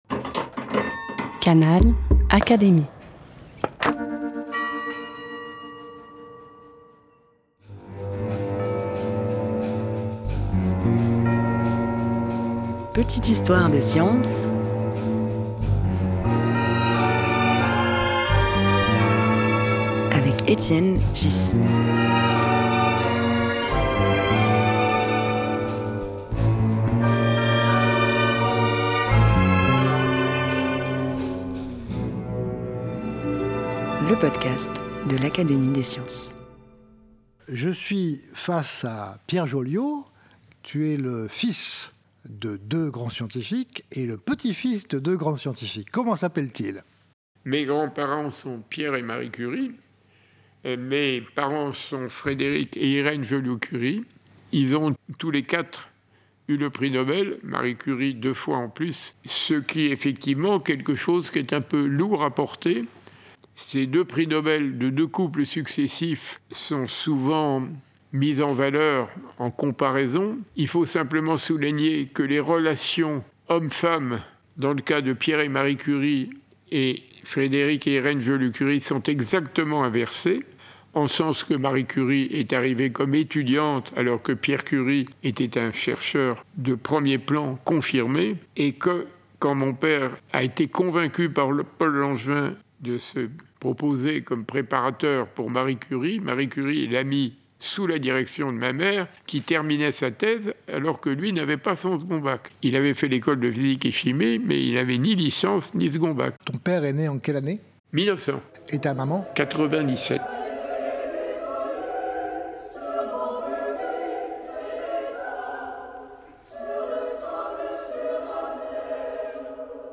Dans cet épisode, Etienne Ghys accueille Pierre Joliot, qui évoque son père, Frédéric, prix Nobel en 1935 avec sa femme Irène pour la découverte de la radioactivité artificielle. Pendant la seconde guerre mondiale, Frédéric était résistant et communiste.
Un podcast animé par Étienne Ghys, proposé par l'Académie des sciences.